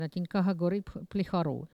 Saint-Jean-de-Monts
Langue Maraîchin
Catégorie Locution